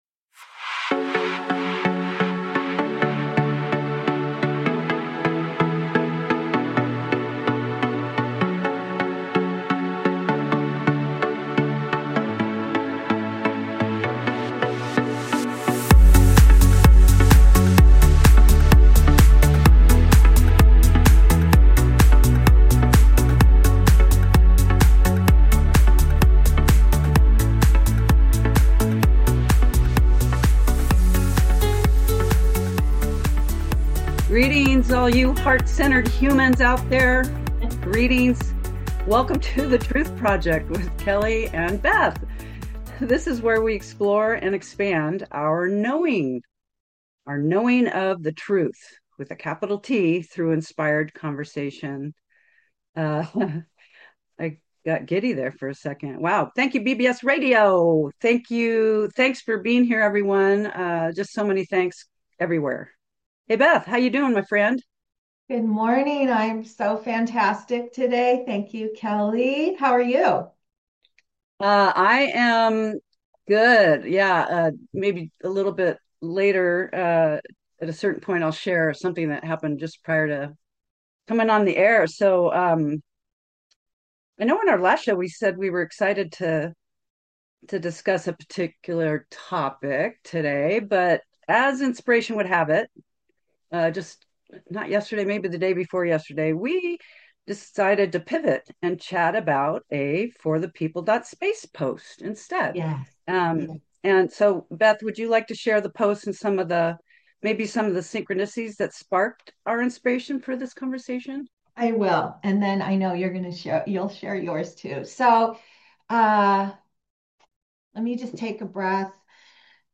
Talk Show Episode, Audio Podcast, The Truth Project and #6: The Power of Creating from Pure Consciousness on , show guests , about Reality is fundamentally energy and consciousness,human beings are rediscovering their ability,Energy First Matter Second,nature—is energy expressed as matter,The Power of Presence,Escaping Time,True power emerges when you are fully present in your body,Creation becomes immediate and intuitive,The mind should act as a servant to the heart,Overthinking disconnects you from truth, categorized as Earth & Space,Health & Lifestyle,Love & Relationships,Philosophy,Physics & Metaphysics,Psychology,Self Help,Society and Culture,Spiritual